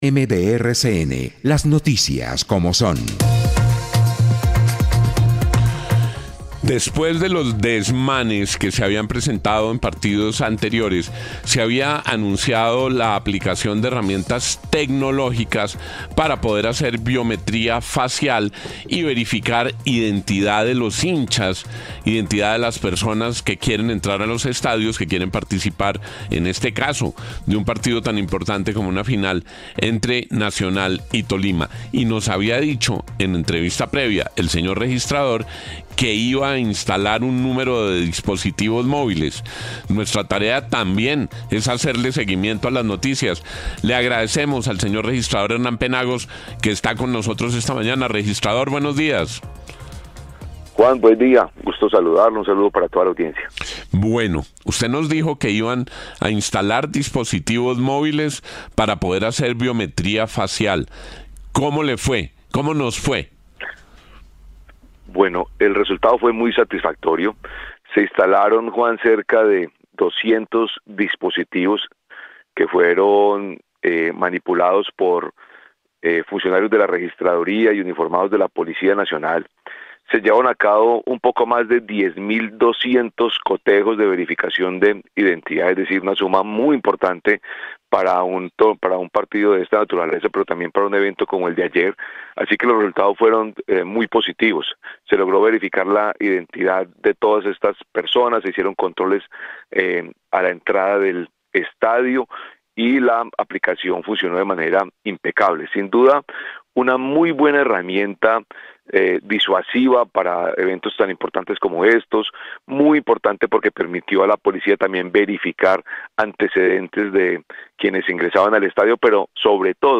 Radio
entrevista